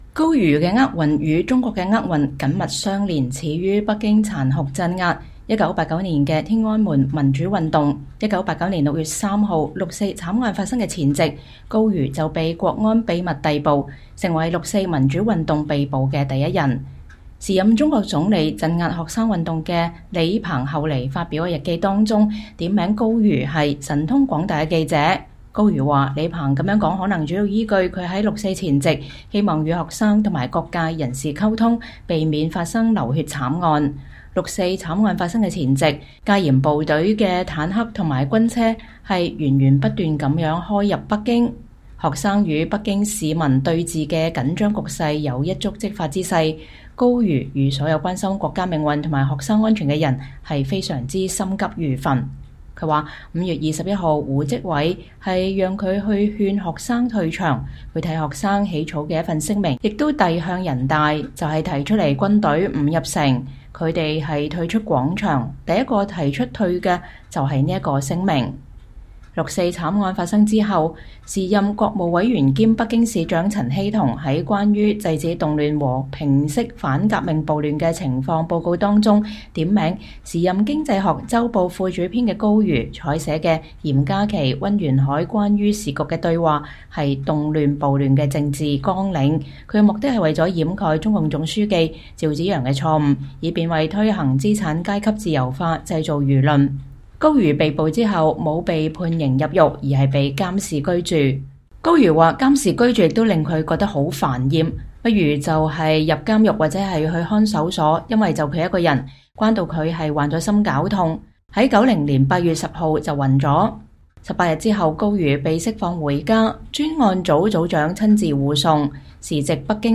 專訪高瑜：歷盡磨難 雖九死其猶未悔